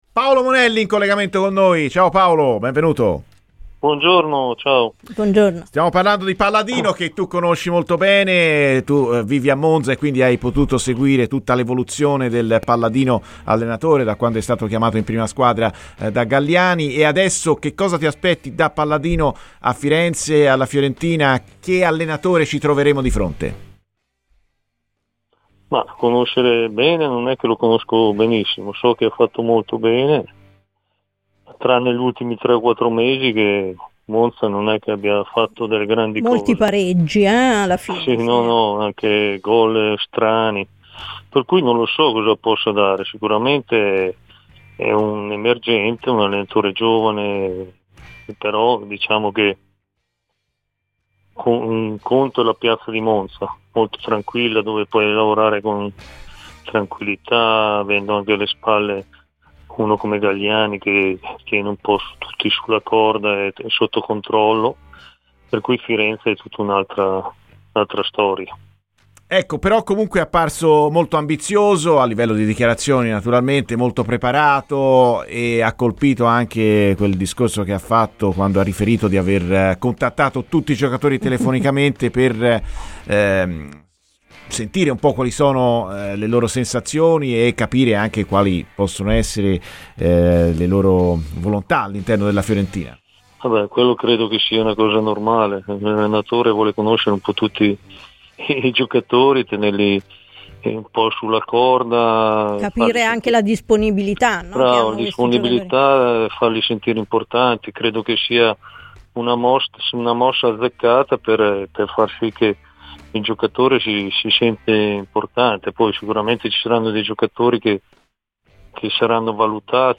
Paolo Monelli, ex attaccante della Lazio e della Fiorentina tra le altre, è intervenuto durante la trasmissione di "Viola amore mio" su RadioFirenzeViola.